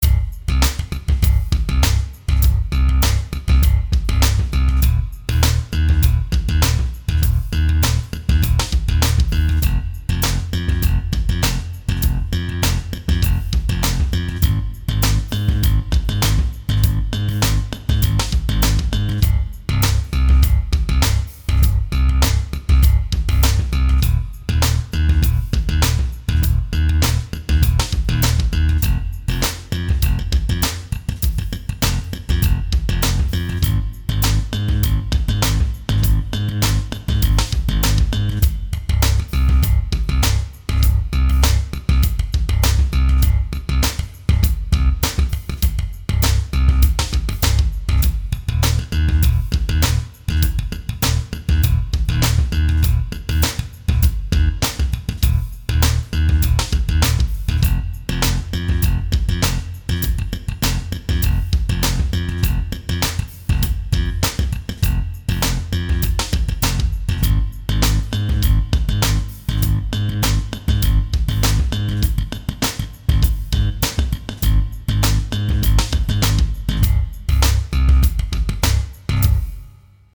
Demo sound